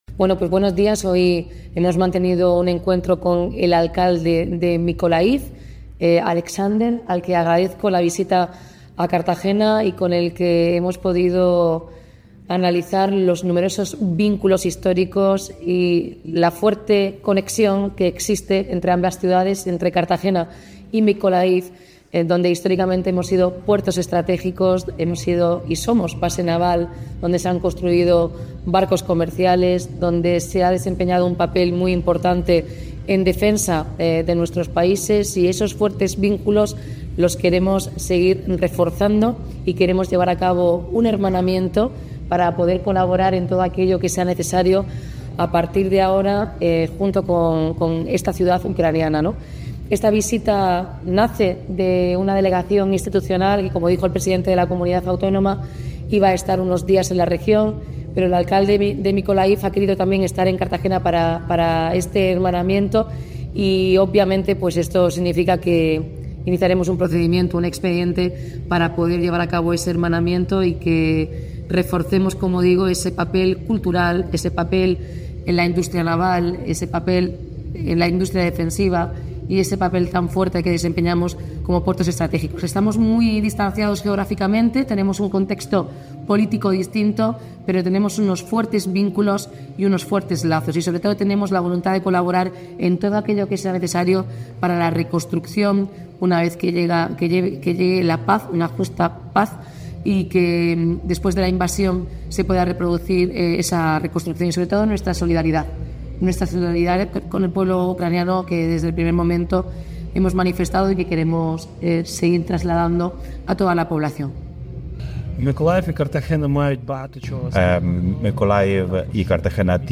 Enlace a Declaraciones de la alcaldesa, Noelia Arroyo, y el alcalde de Mykolaiv